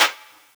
Outlaw Clap.wav